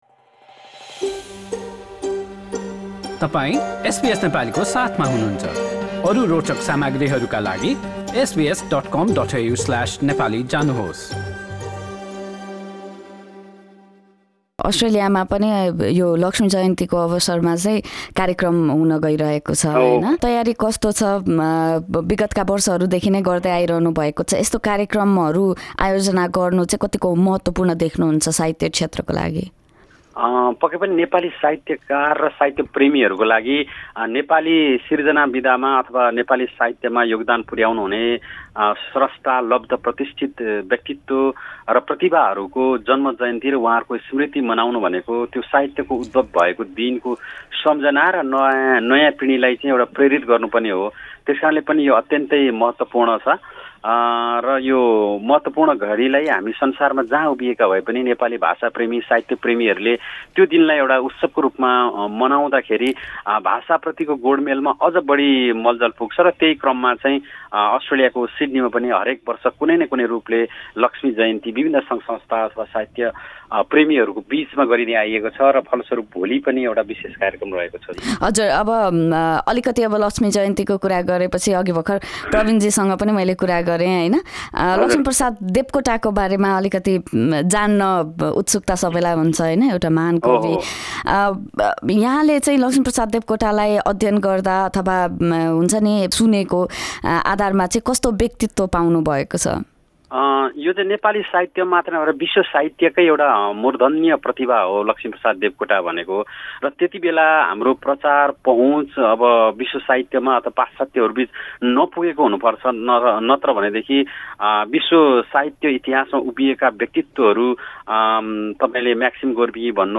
कुराकानी